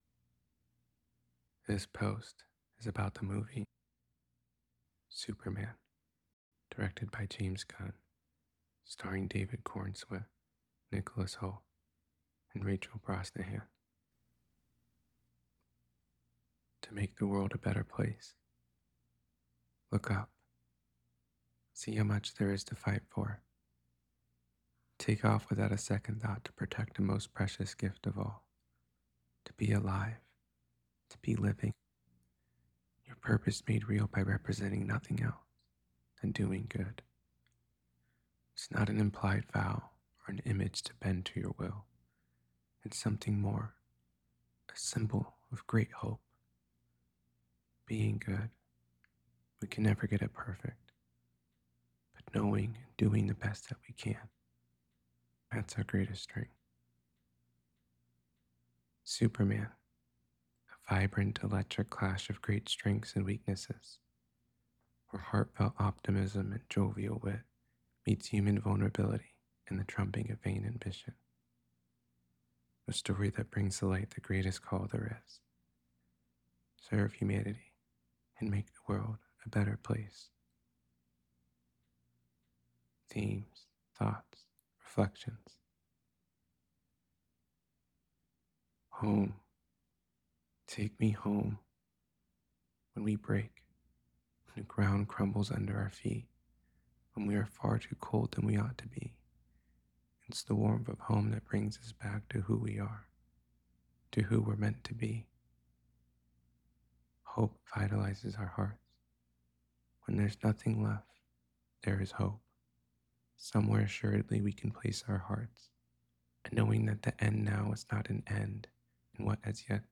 superman-to-know-a-story-reading.mp3